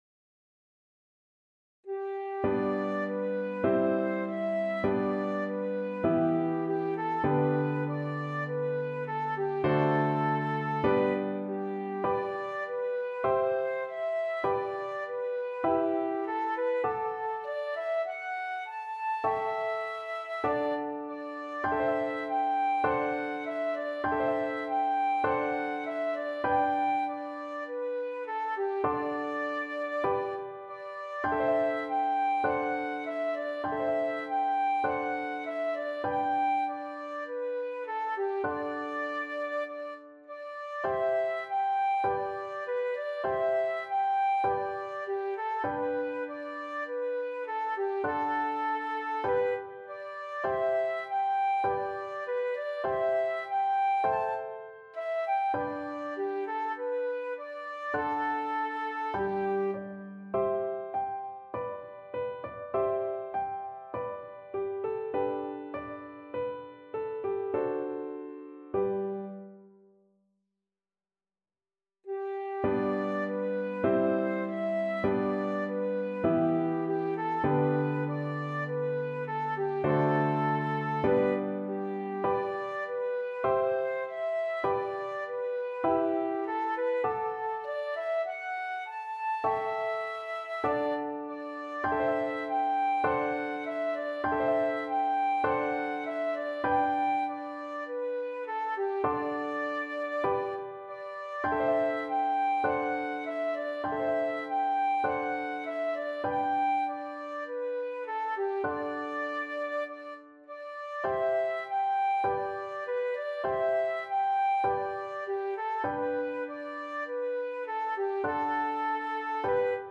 Moderato
Flute  (View more Easy Flute Music)
Classical (View more Classical Flute Music)